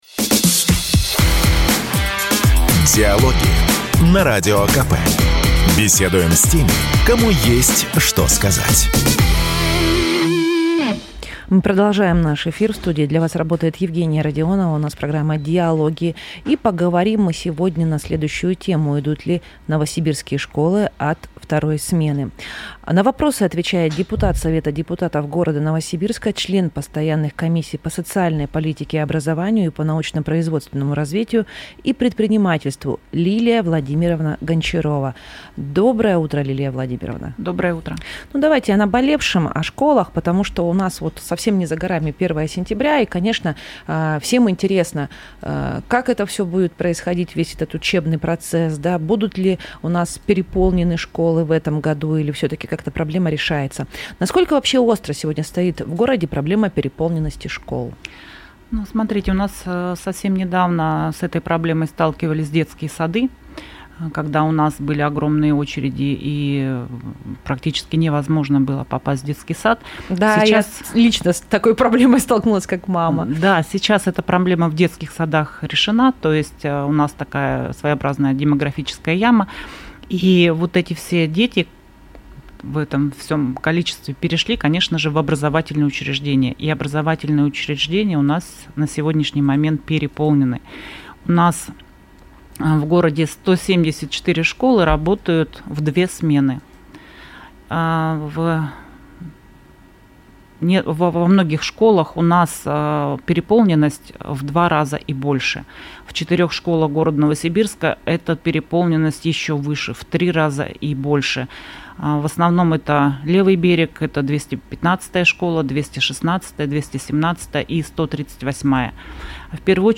Запись программы, транслированной радио "Комсомольская правда" 27 августа 2025 года Дата: 27.08.2025 Источник информации: радио "Комсомольская правда" Упомянутые депутаты: Гончарова Лилия Владимировна Аудио: Загрузить